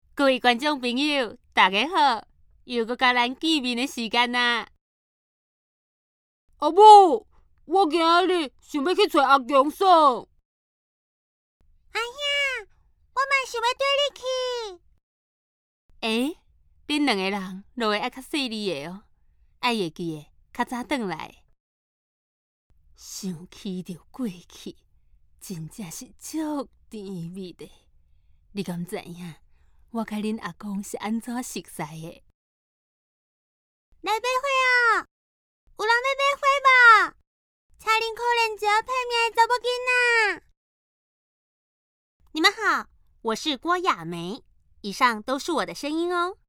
台語配音 國語配音 女性配音員
她能完美詮釋角色情緒的轉變，從大笑到落淚皆游刃有餘。